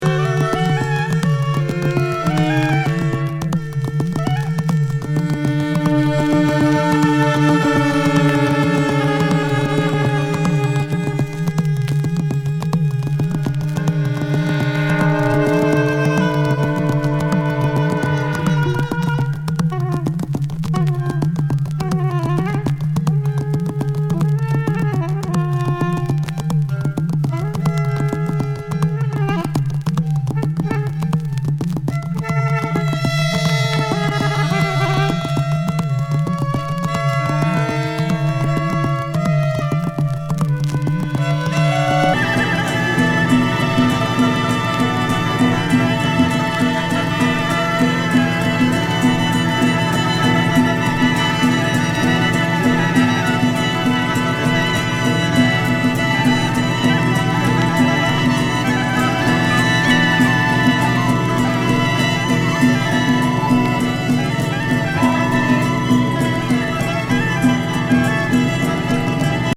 ヨーロッパ牧歌的民俗音楽インプロ+チェンバーロック。